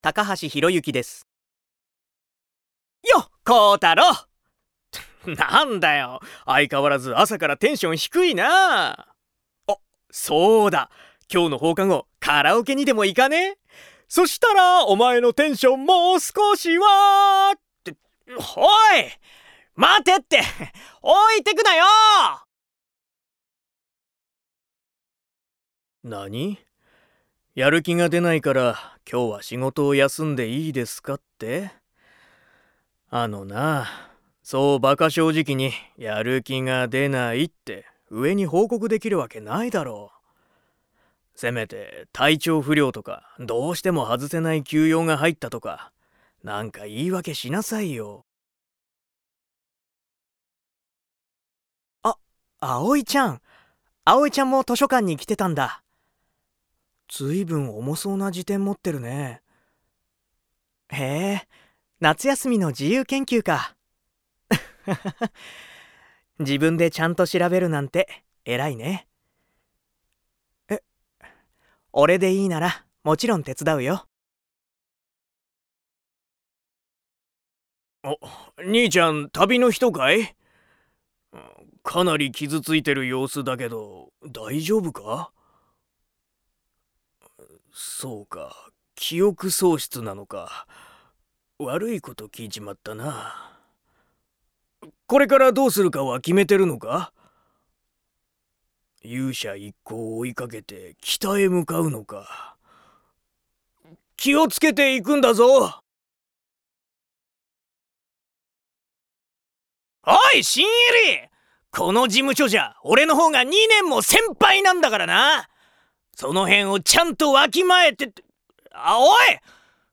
方言： 仙台弁
VOICE SAMPLE